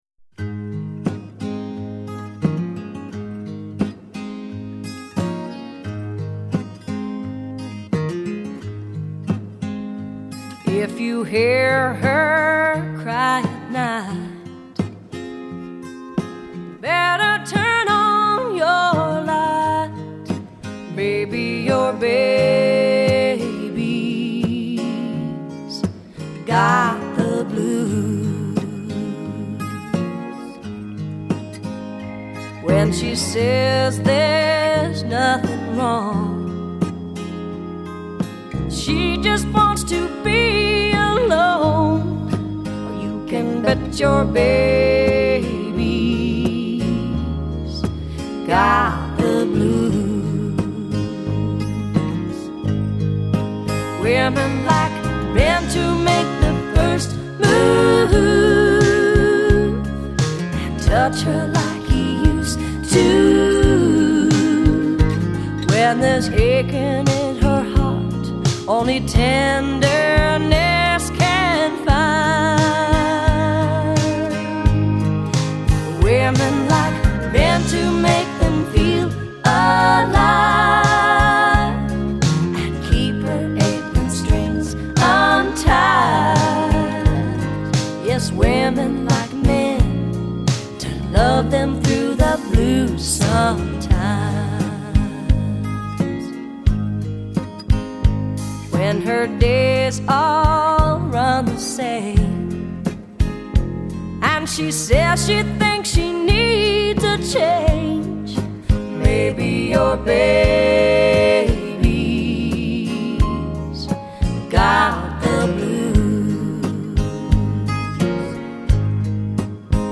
专辑一次性囊括了民谣、流行、蓝调、爵士等各领域里的发烧女声，不仅在编曲方面下足了苦心，引以为傲的演唱技巧更是多姿多采。
简单的乐器把这些耳熟能祥的经典老歌重新演奏，再加上女伶们引以为傲的演唱技巧，每首曲子都是一段爱的故事，值得您细细体验。